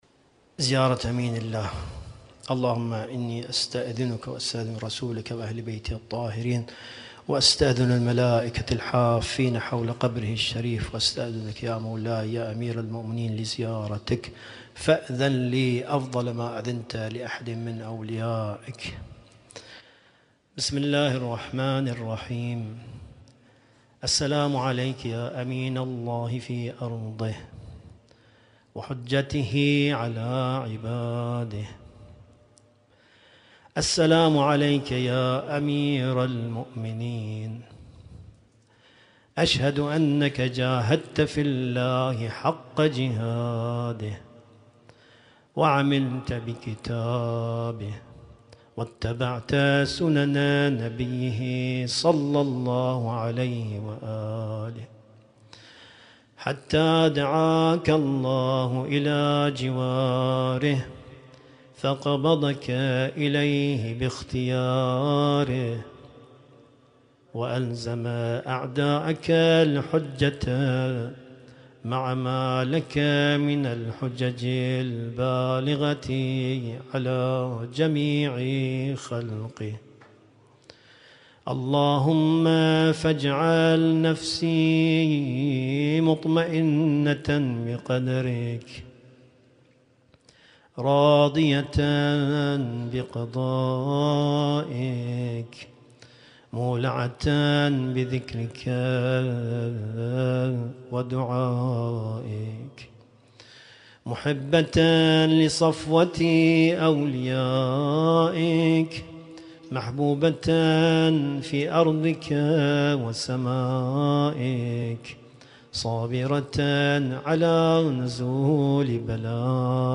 القارئ: